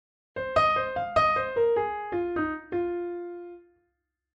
Here are two blues licks in the key of F. Both are using notes of the F blues scale.
Lick 1 starts with triplets and ends with swinging eighth notes that finish on the root note F.
Blues Piano Lick 1
blues-lick-1-in-f.mp3